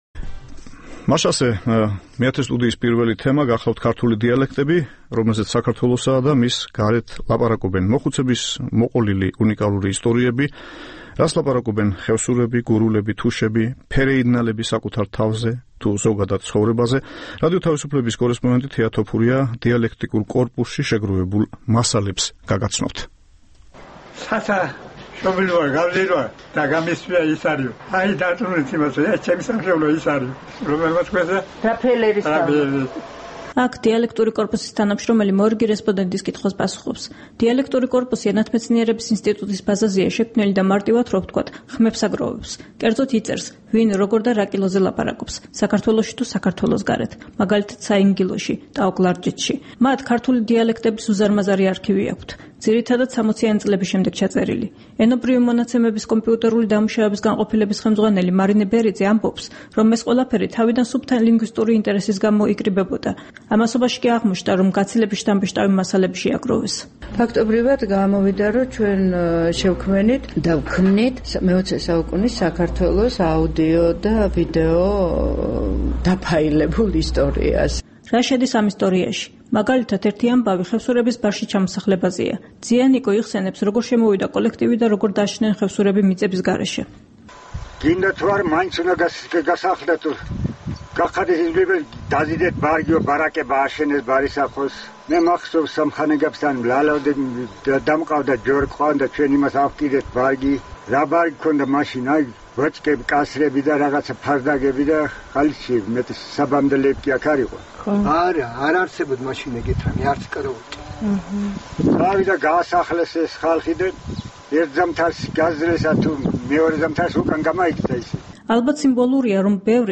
„გაზაფხულზე გამწვანდებისყე“ - ქართულ დიალექტებზე მოთხრობილი ამბები